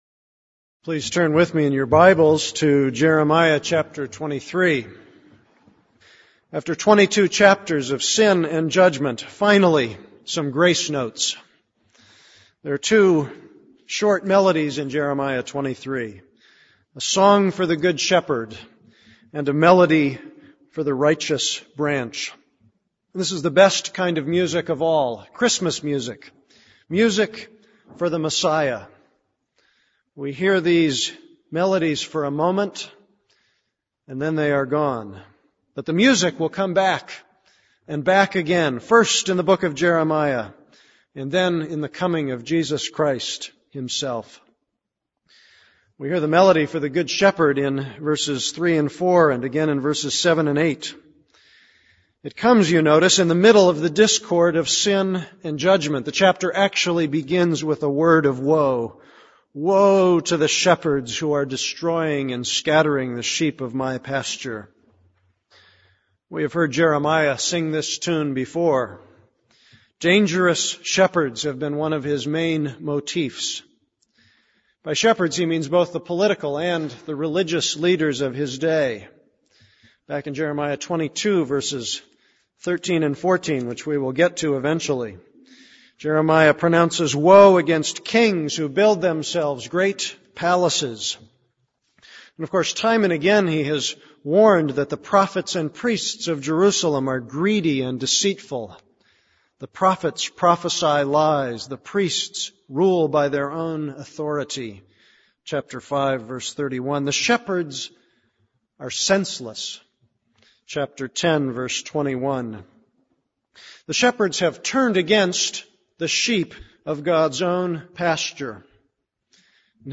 This is a sermon on Jeremiah 23:1-8.